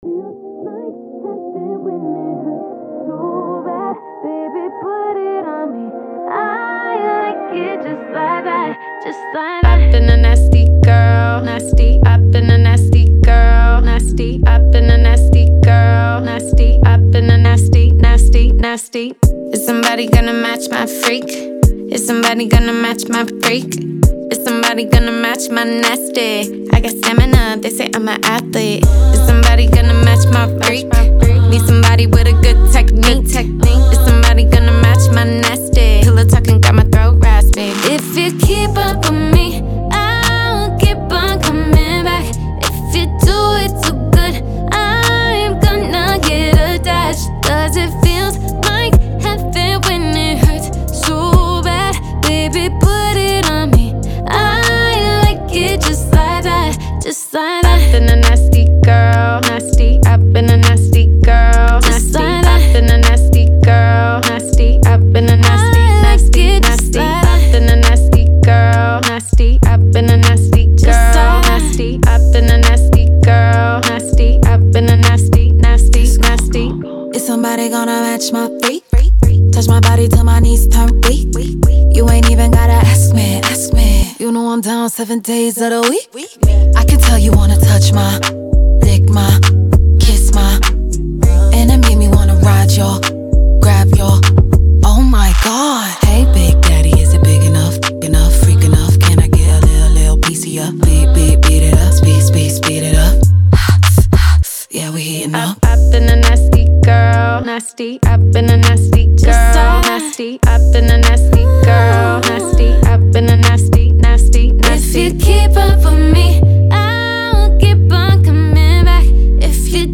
Genre: R&B.